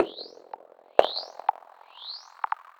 Abstract Rhythm 31.wav